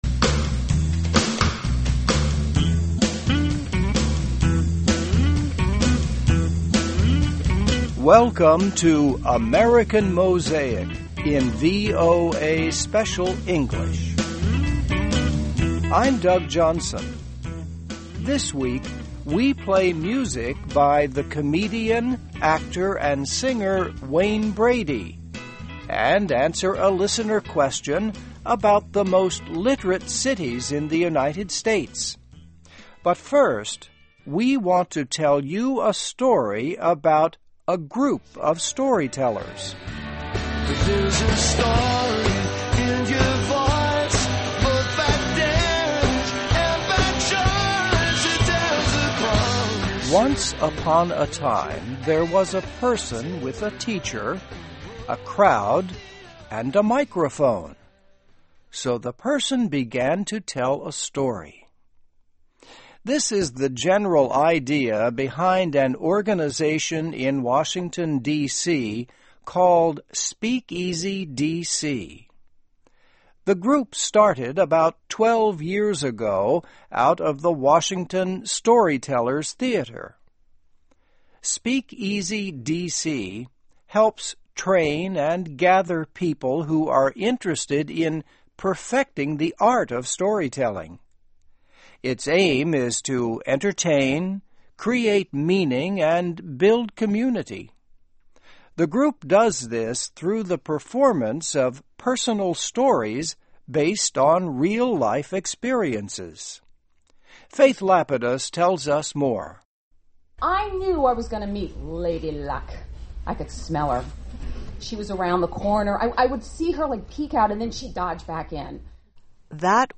Welcome to AMERICAN MOSAIC in VOA Special English.